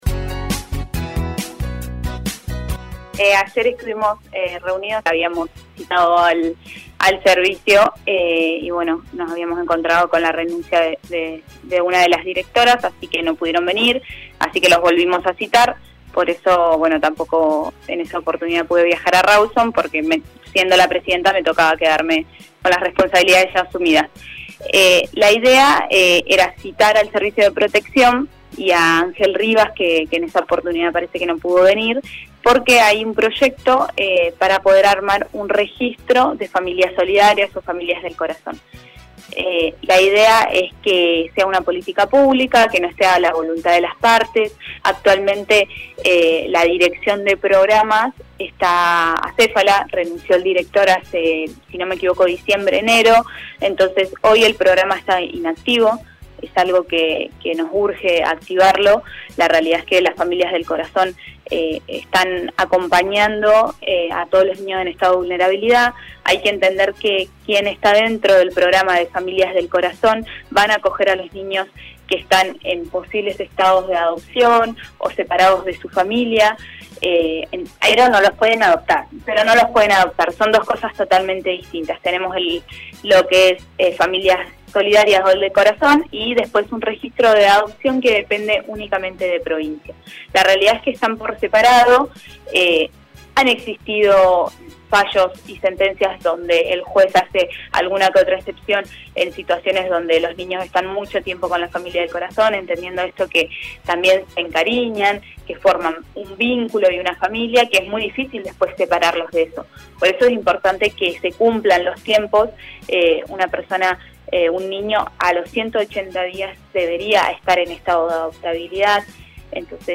La concejal del bloque Despierta Comodoro dialogó con LA MAÑANA DE HOY y comentó de la reunión con el Servicio de Protección y la oficina de Desarrollo humano municipal y provincial con el fin de avanzar en el proyecto de crear un registro de familias solidarias o familias del corazón.